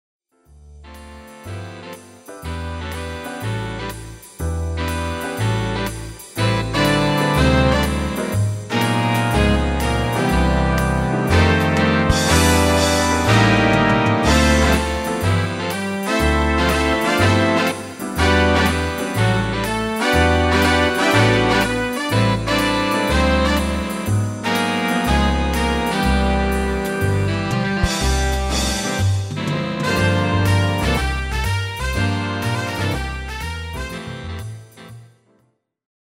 Demo/Koop midifile
Taal uitvoering: Instrumentaal
Genre: Jazz / Big Band